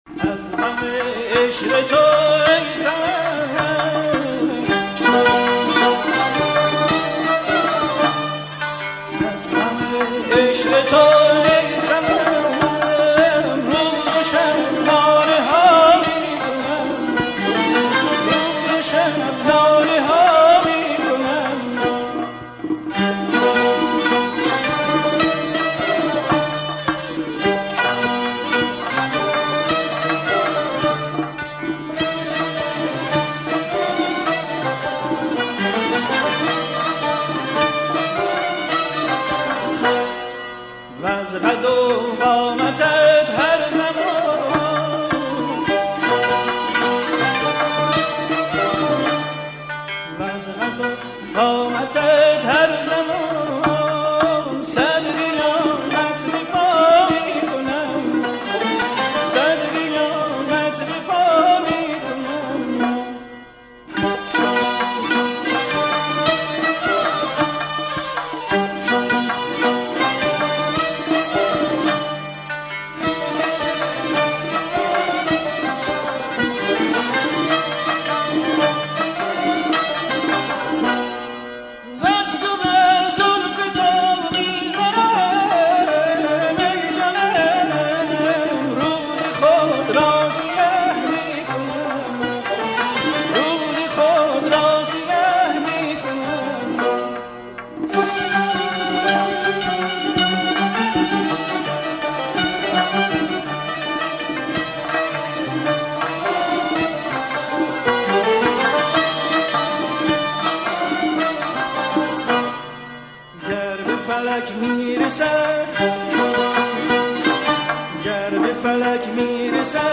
دستگاه سه‌ گاه